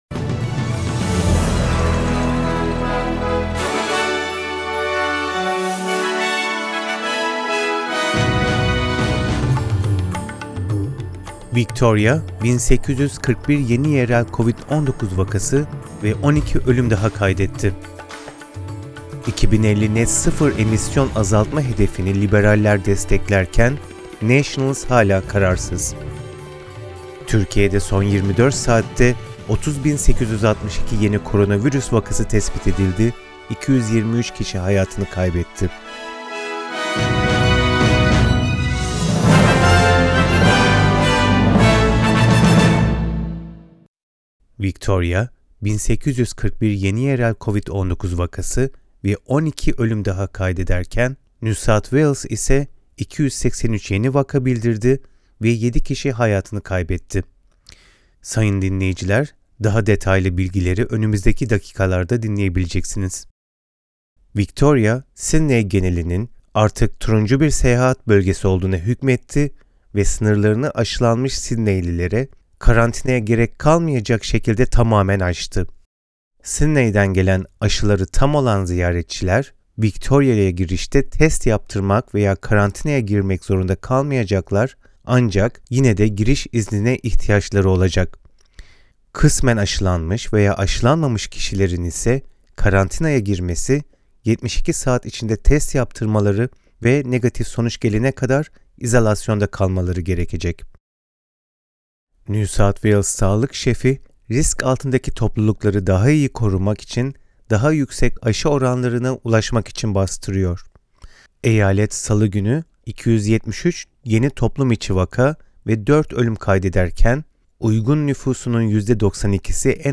SBS Türkçe Haberler Source: SBS